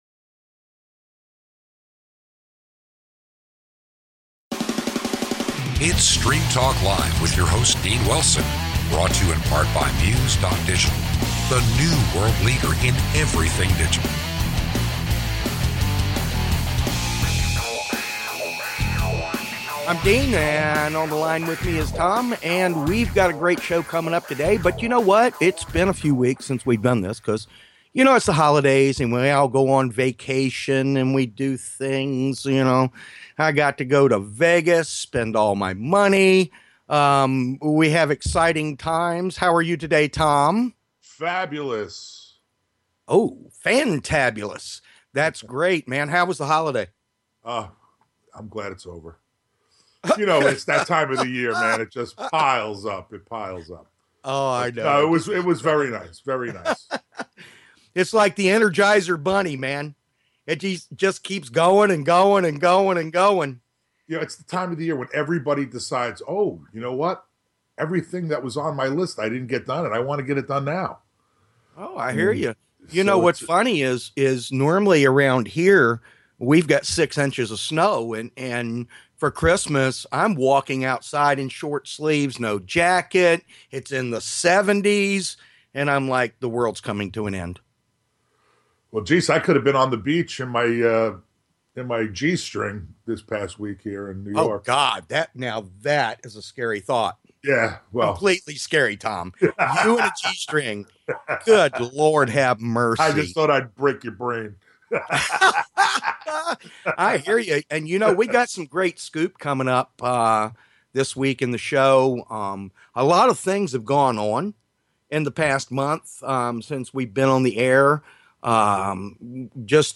Talk Show Episode
Interview